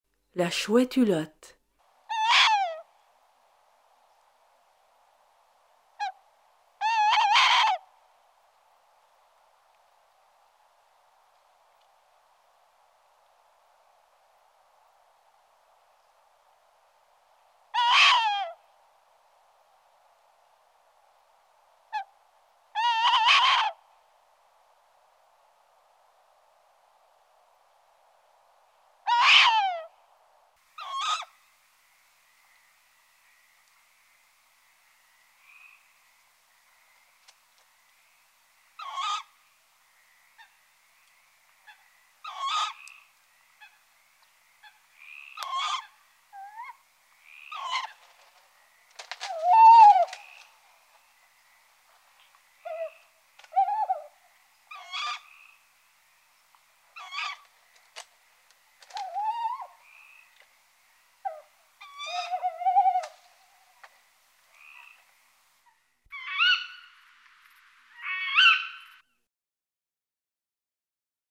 oiseau
chouettehulotte.mp3